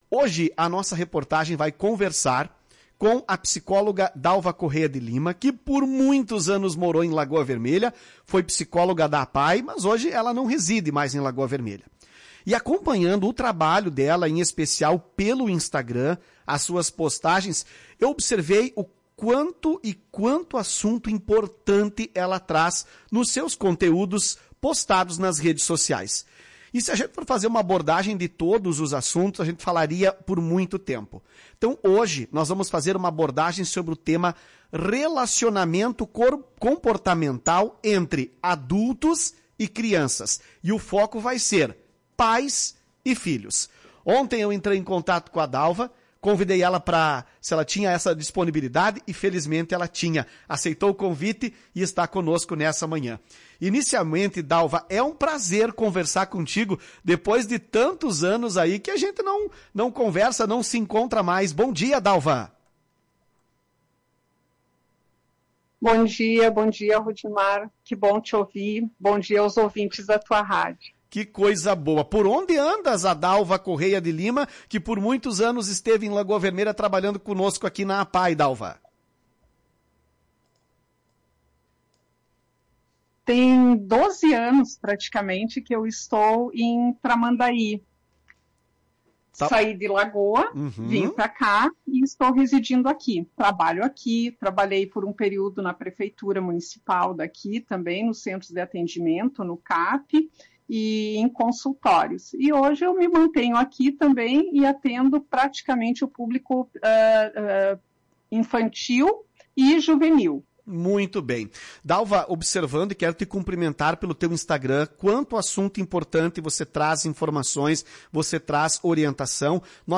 entrevista a Tua Rádio Cacique.